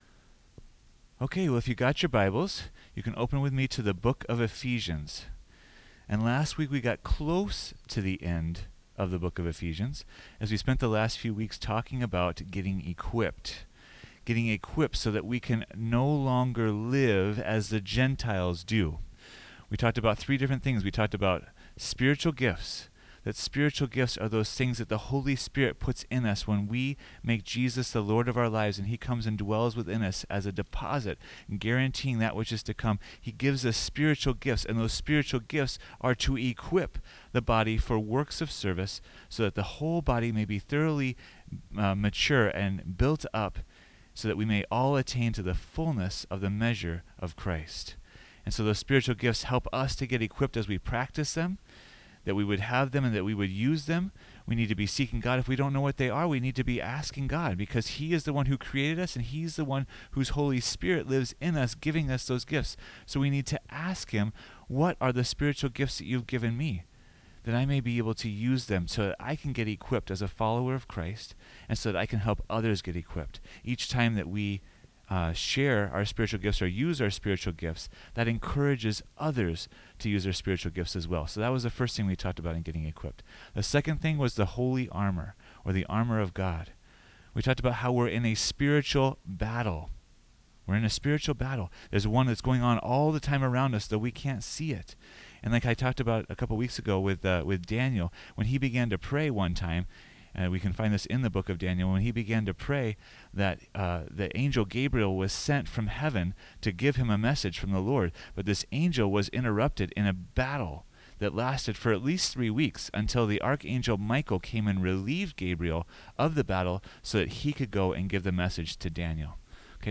2023 Forgiveness Is A Reflection Preacher